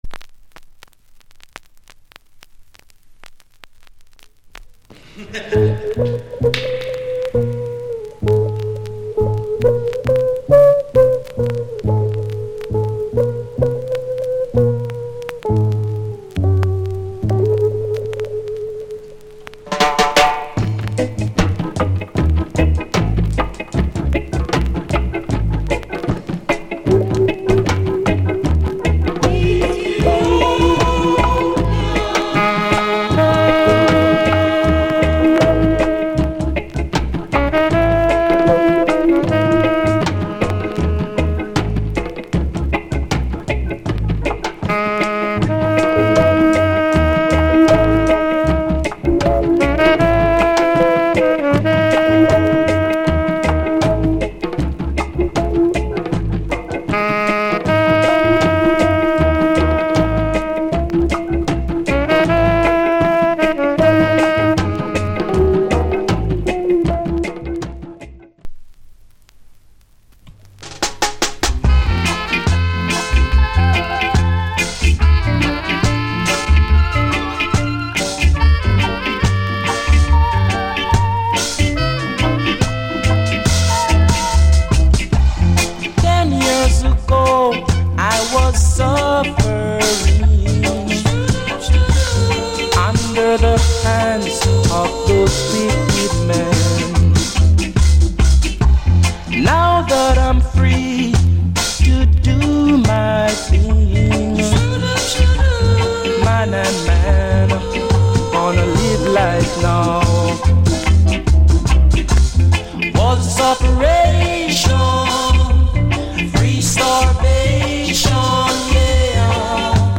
Genre Reggae70sEarly / Inst